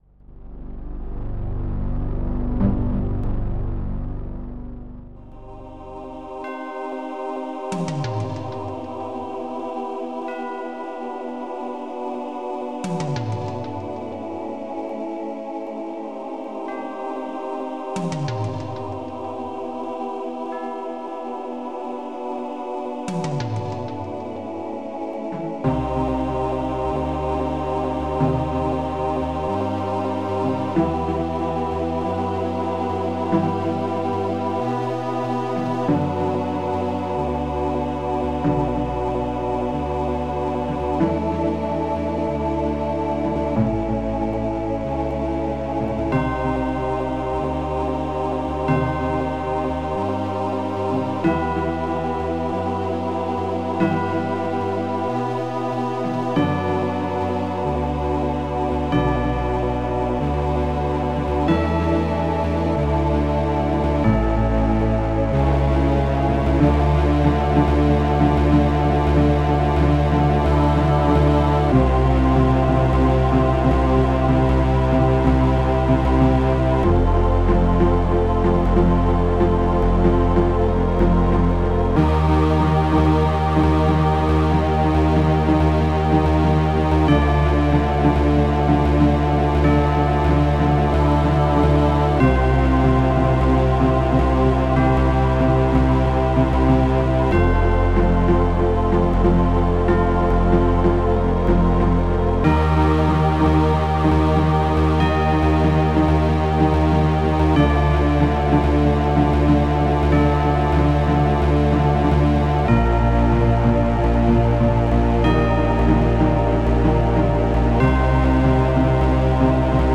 AvatarDeepstring
AvatarSnare